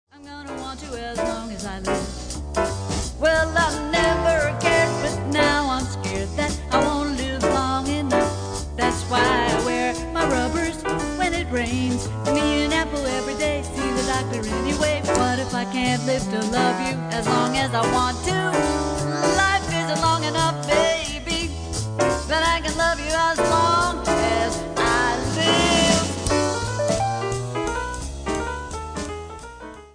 NOTE: Background Tracks 1 Thru 10